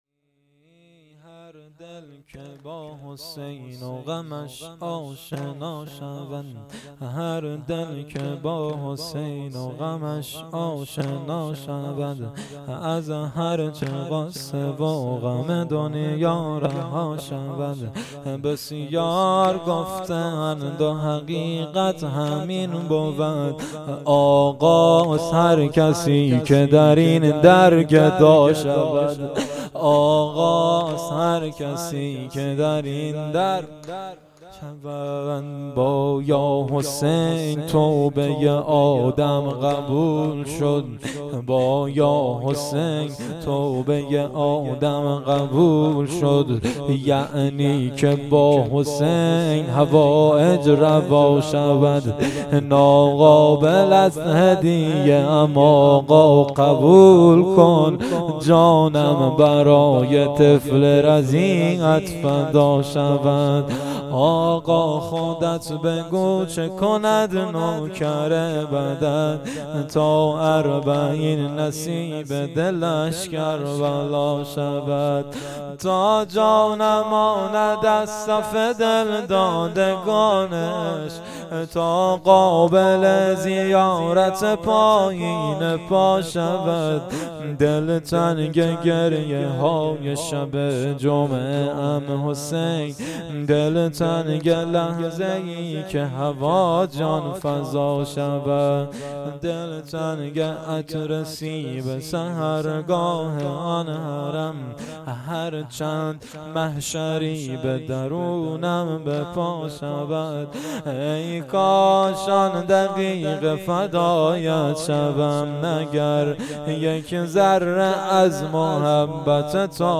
مراسم عزاداری دهه اول محرم الحرام 1399 - مسجد صاحب الزمان (عج) هرمزآباد